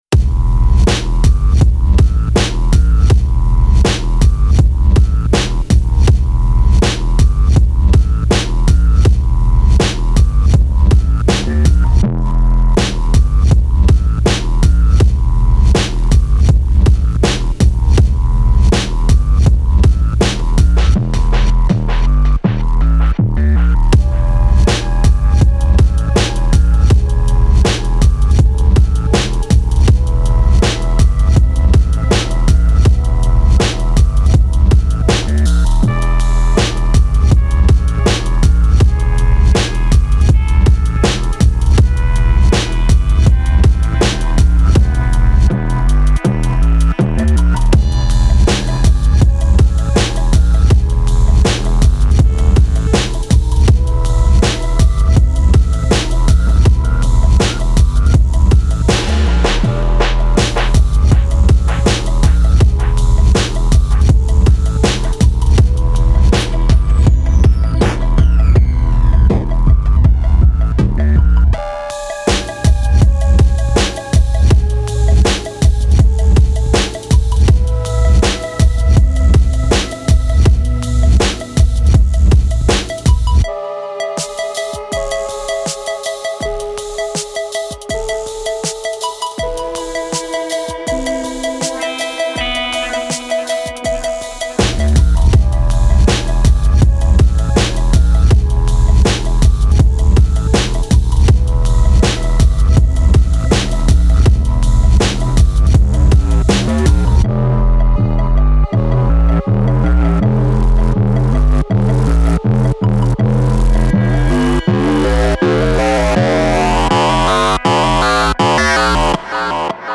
digitakt and digitone